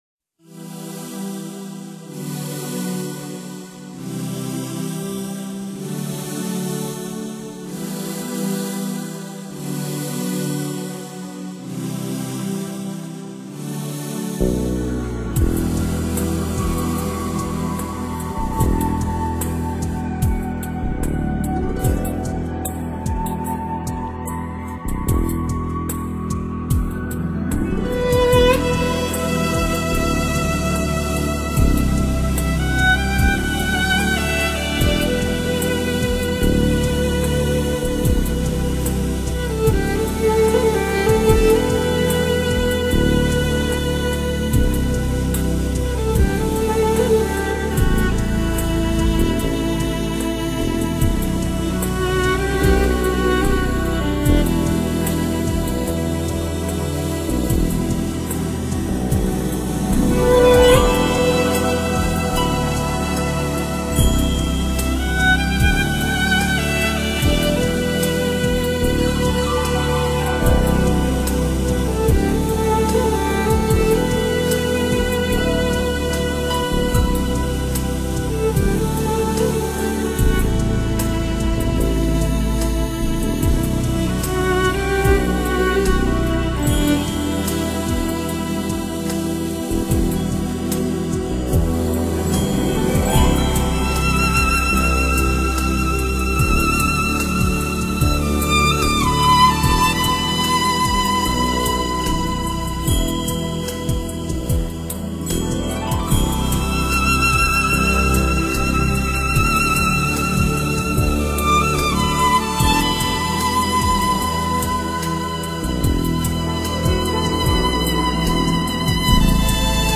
[2005-10-23]心灵的呼唤[小提琴曲]